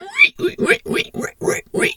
pgs/Assets/Audio/Animal_Impersonations/pig_2_hog_seq_05.wav at master
pig_2_hog_seq_05.wav